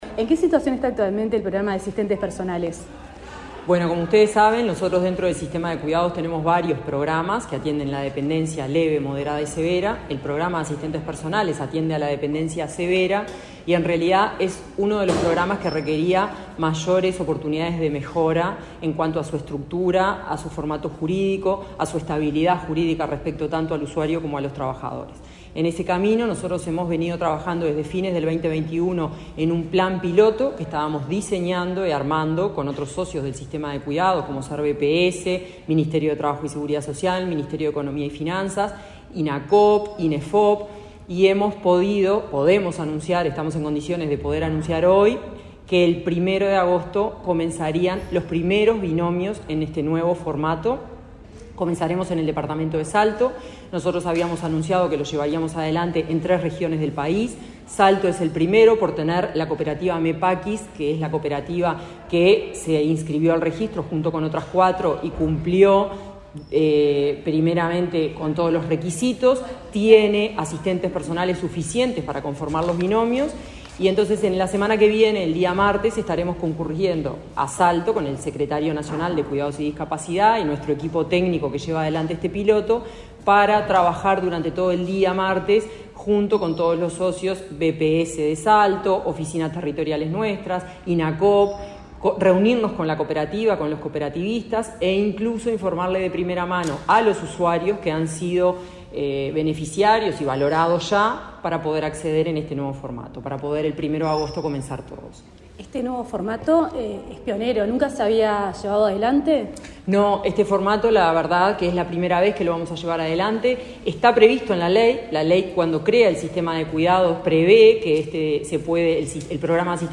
Entrevista a la directora de Cuidados del Mides, Florencia Krall
El Sistema de Cuidados presentará, el próximo 1 de agosto, un plan piloto de nueva modalidad del Programa de Asistentes Personales, ahora en formato de cooperativas. La directora de Cuidados del Ministerio de Desarrollo Social (Mides), en entrevista con Comunicación Presidencial, este 22 de julio, explicó la iniciativa y amplió sobre las becas de inclusión socioeducativas.